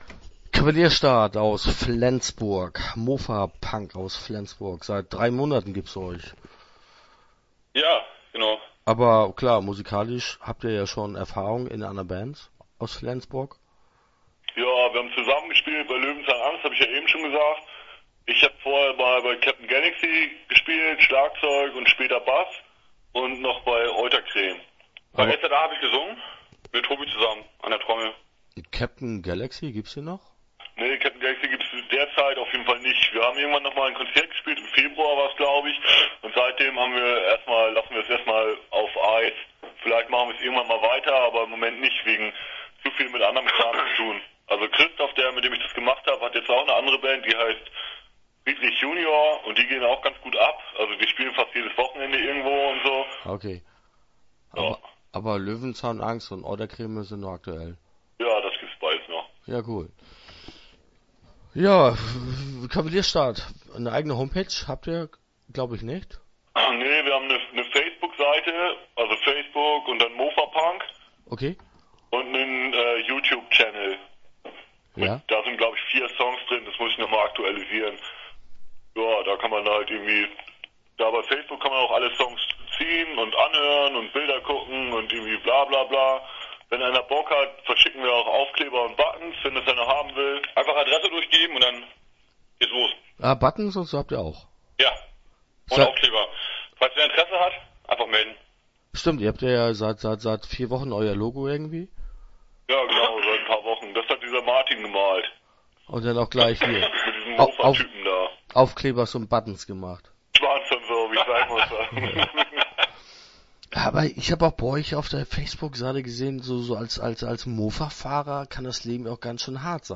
KavalierSTART - Interview Teil 1 (9:40)